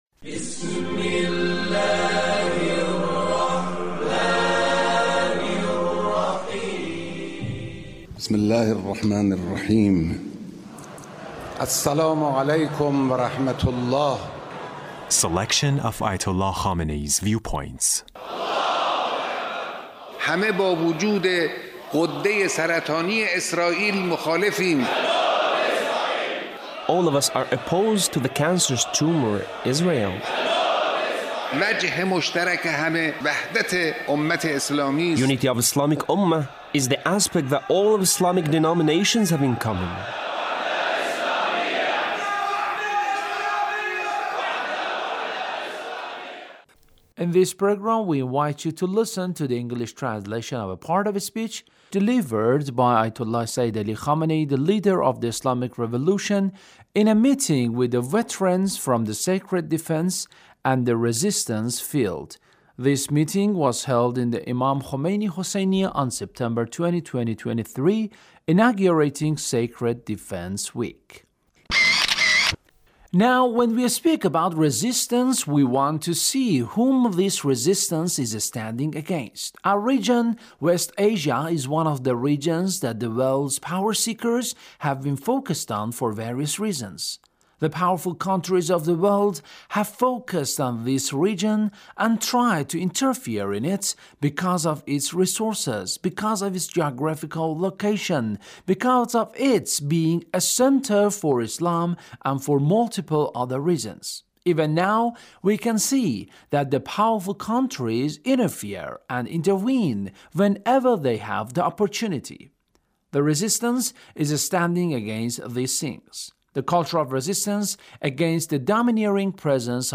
Leader's Speech (1858)
Leader's Speech on Sacred Defense